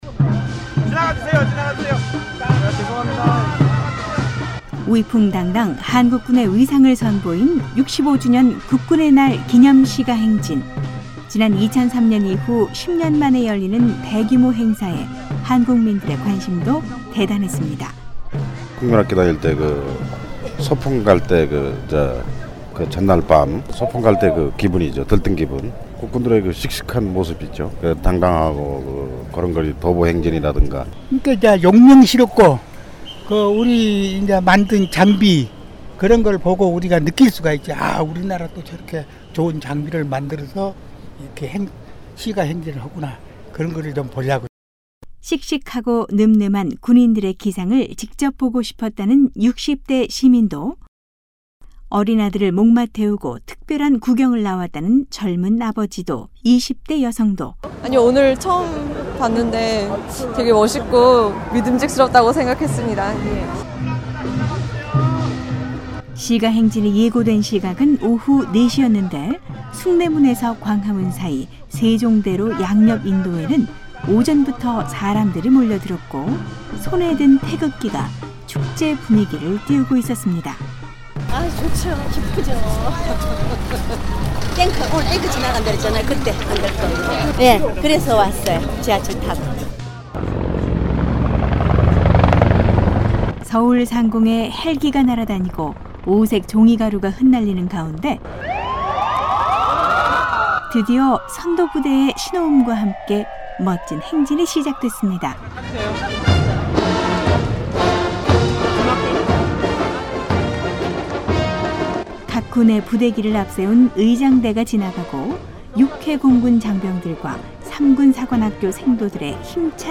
지난 2008년 숭례문 화재로 취소된 이후 10년만에 열린 이번 국군의 날 시가행진에는 4800여명의 군인들이 참가했고, 190여종의 최첨단 신무기들이 선보여 큰 호응을 받았습니다. 서울 세종대로 시가행진 현장으로 안내하겠습니다.